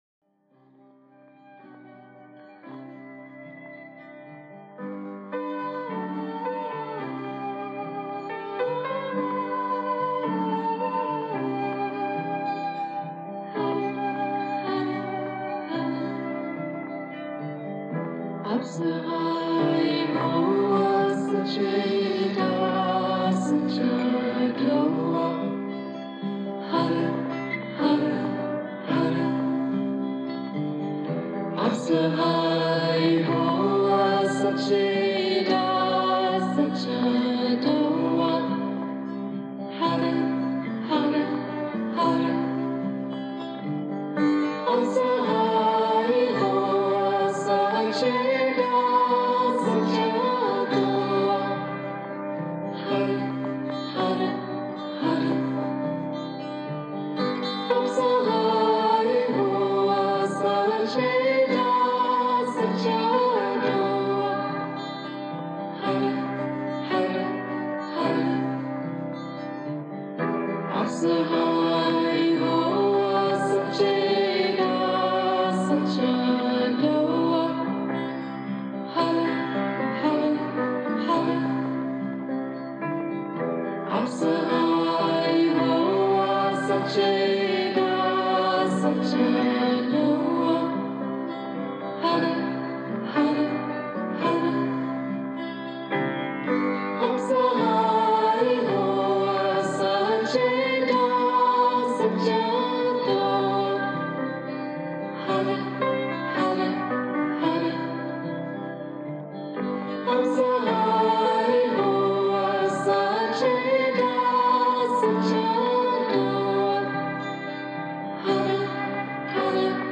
Segue abaixo meditação da prosperidade.